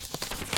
x_enchanting_scroll.4.ogg